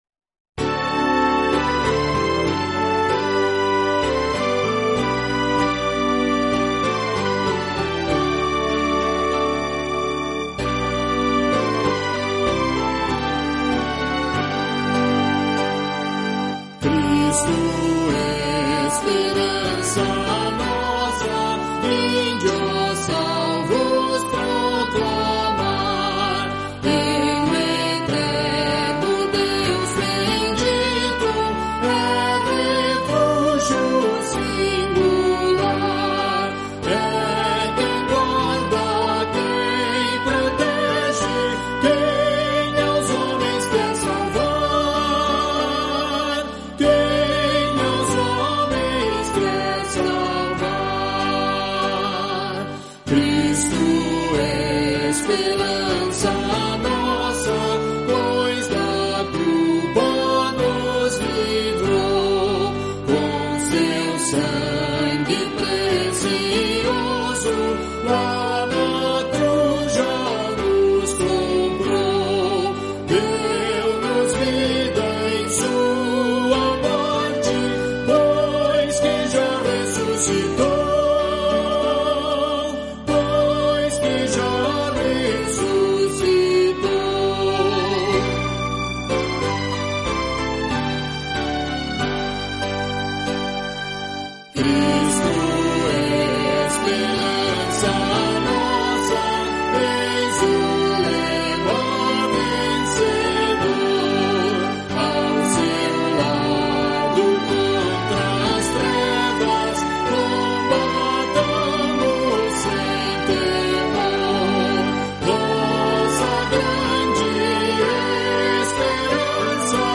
Terceira série de um total de quatro, que nos mostra reflexões que chamamos de Dedinhos de Prosa. Esses dedinhos nos os gravamos aos microfones da Rádio Você, em Americana, interior do Estado de São Paulo.